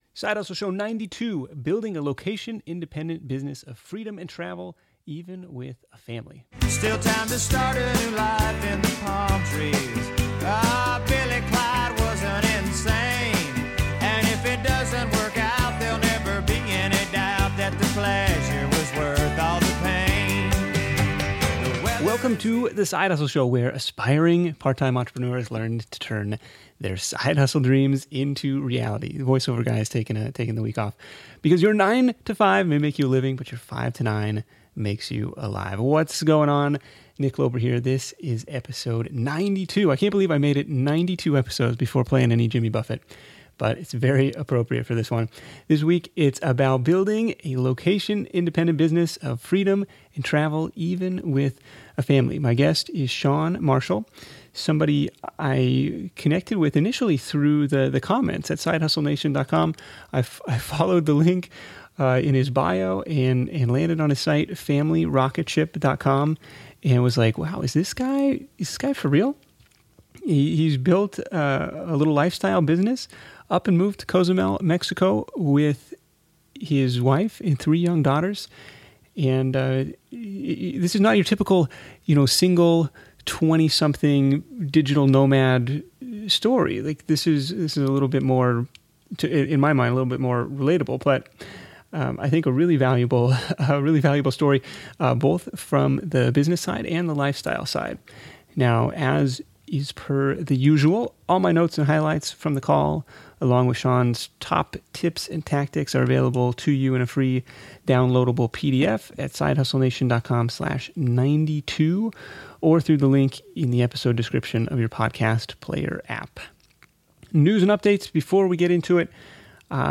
This is a fun chat about hustling for business and building a lifestyle on your own terms.